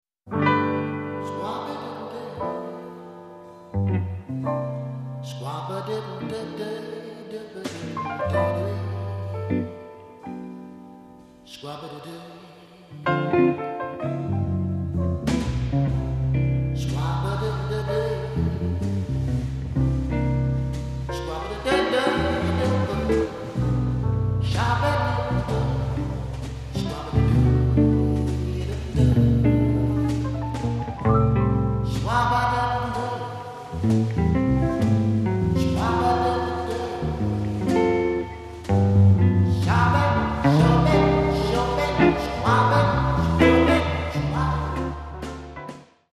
Crime Jazz at its best!